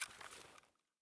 EFT Aim Rattle / gamedata / sounds / weapons / rattle / lower / lower_8.ogg